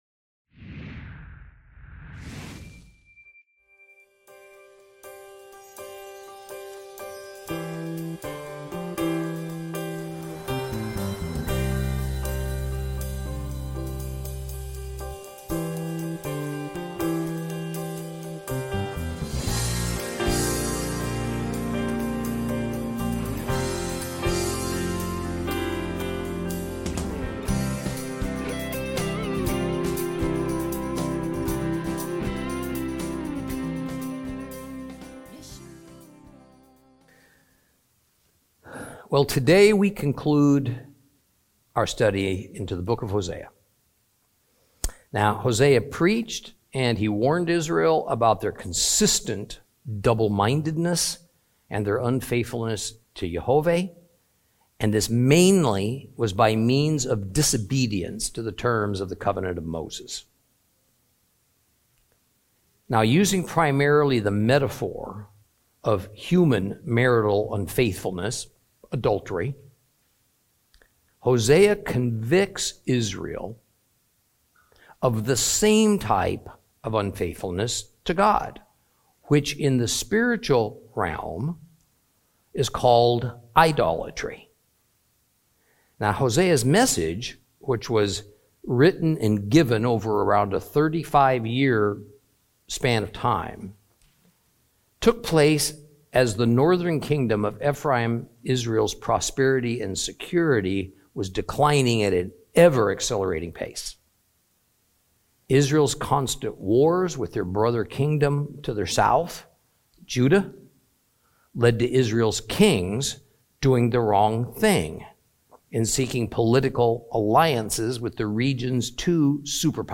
Teaching from the book of Hosea, Lesson 24 Chapter 14.